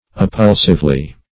Appulsively \Ap*pul"sive*ly\